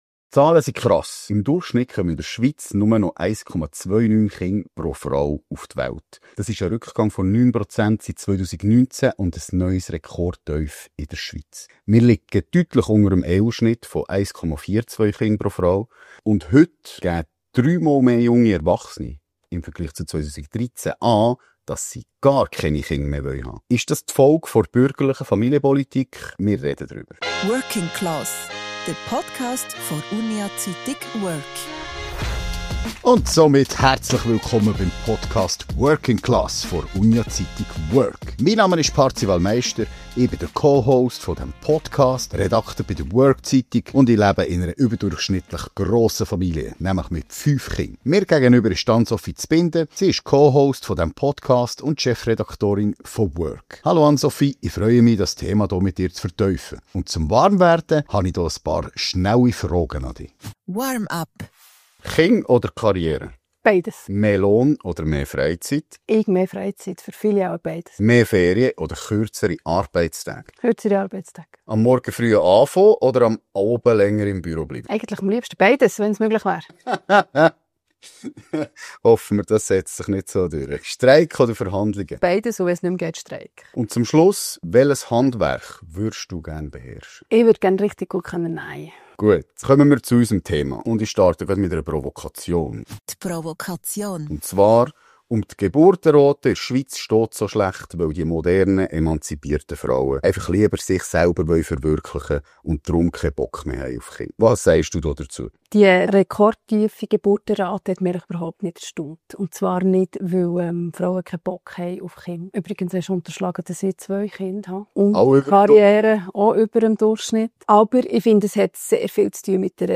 Das Gespräch bietet konstruktive Kritik und zeigt Lösungsansätze auf.